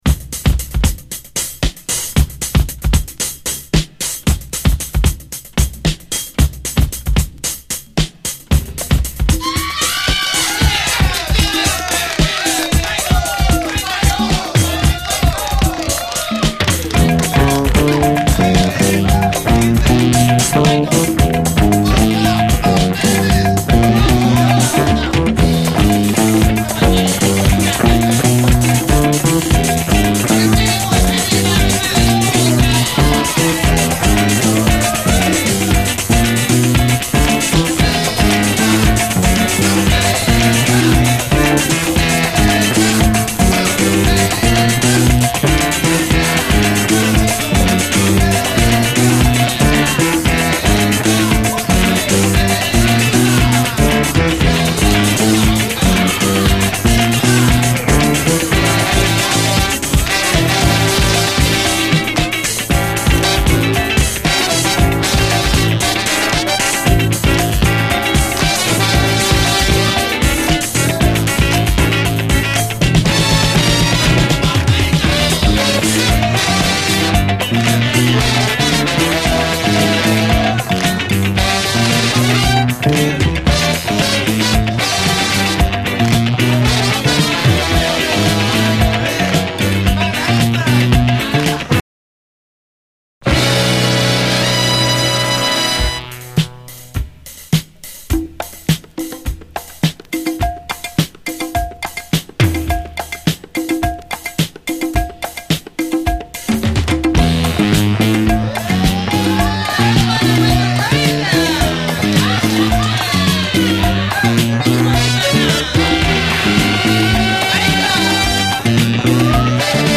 ソウル・ヴォーカル・グループの神髄
眩しいほどの幸福感が半端ではない黄金の一曲！